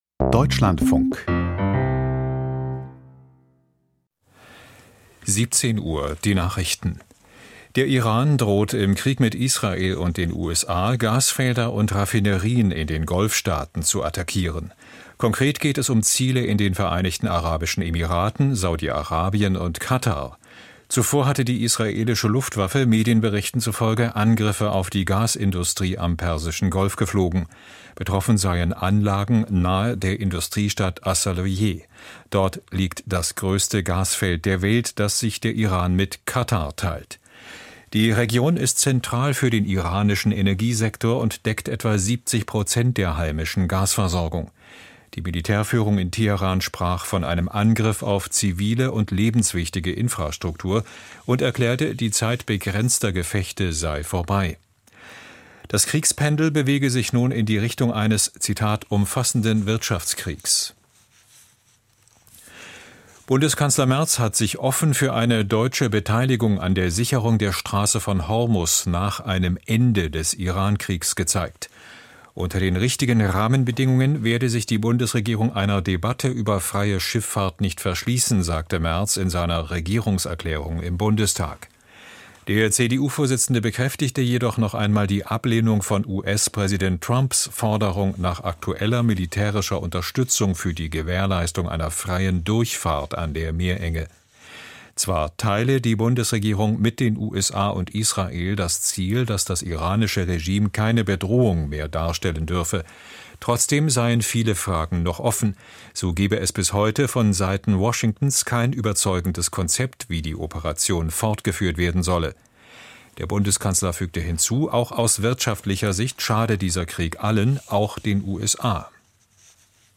Die Nachrichten vom 18.03.2026, 17:00 Uhr
Aus der Deutschlandfunk-Nachrichtenredaktion.